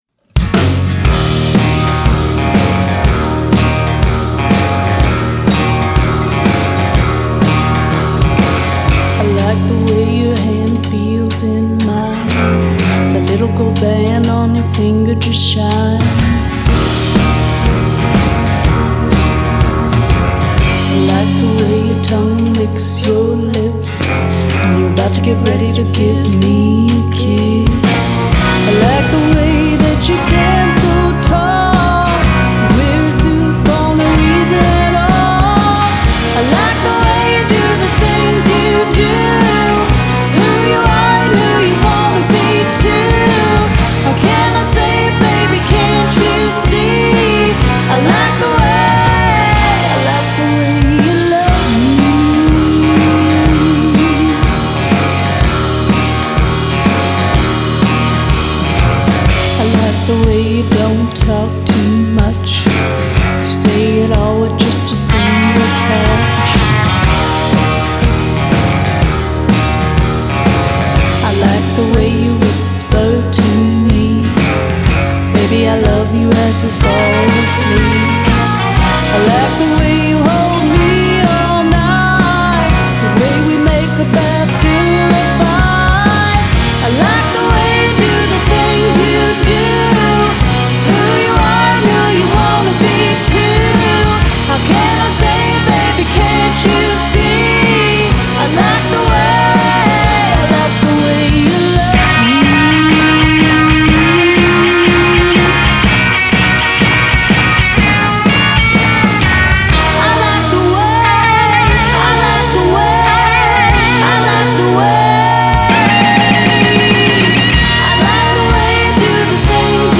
Genre: Country, Folk, Indie, Singer-songwriter